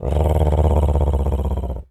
Animal_Impersonations
cat_purr_deep_01.wav